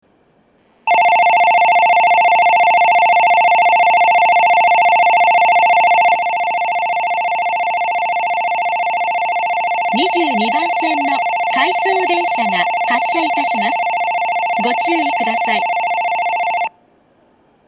発車時には全ホームROMベルが流れます。
遅くとも東北新幹線全線開業時には、発車ベルに低音ノイズが被るようになっています。ただし、新幹線の音がうるさいので密着収録していてもほとんどわかりません。
２２番線発車ベル 回送電車の放送です。